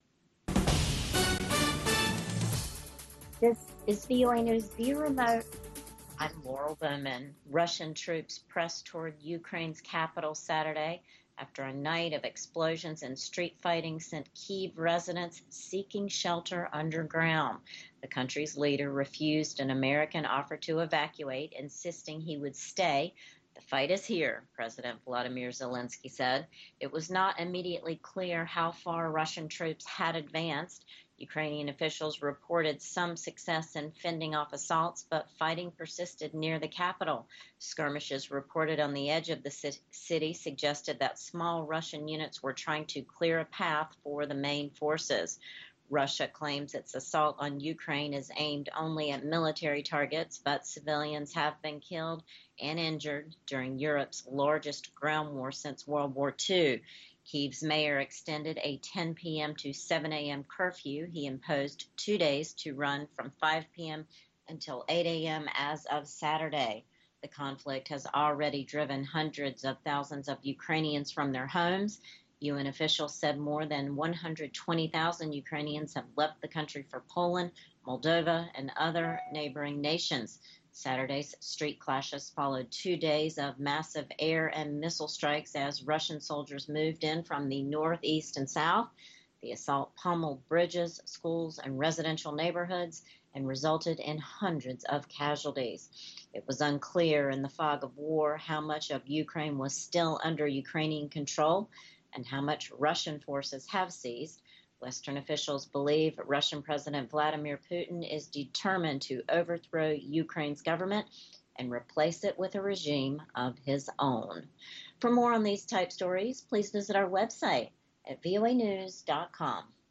Voice of America: VOA Newscasts